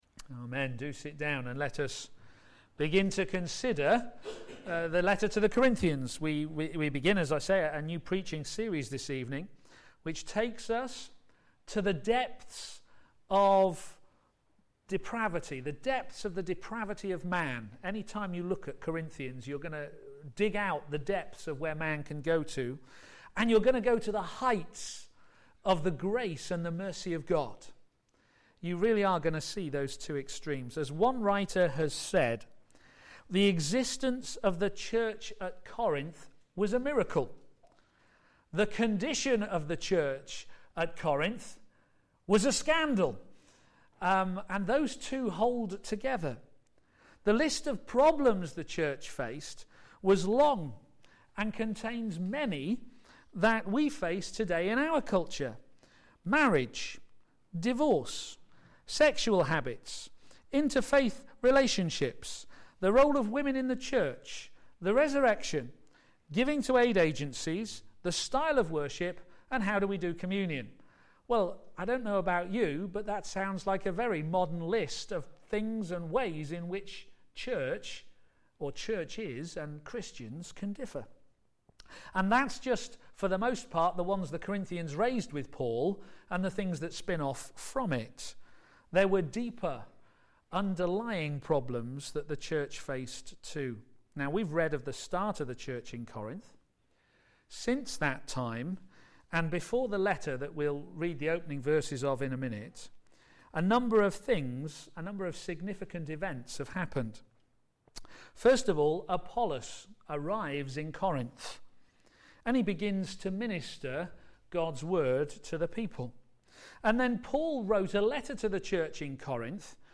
Media for p.m. Service
Working together to advance the Gospel Theme: A greeting that emphasises unity Sermon